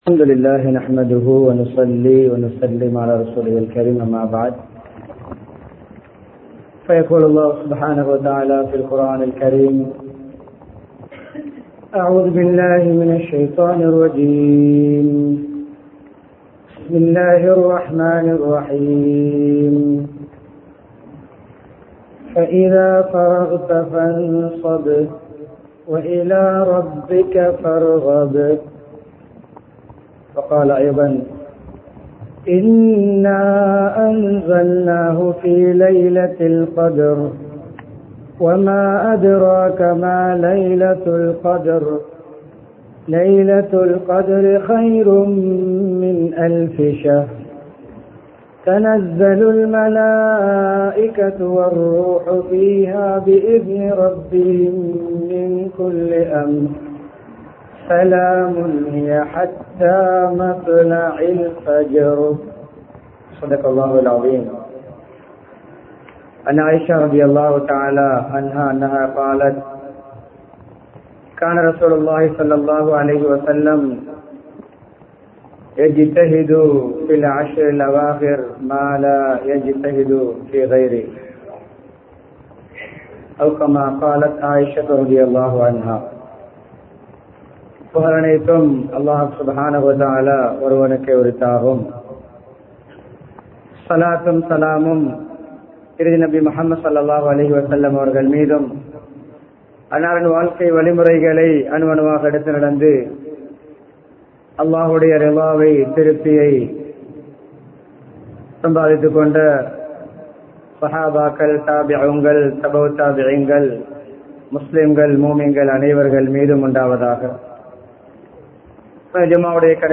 Malwana, Raxapana Jumua Masjidh